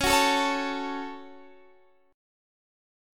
Dbsus4#5 chord